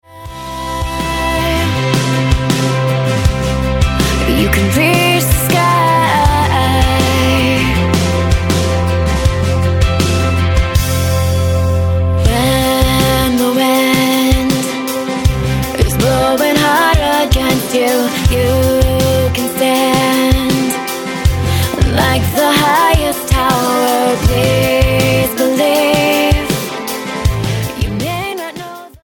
Style: MOR/Soft Pop Approach: Praise & Worship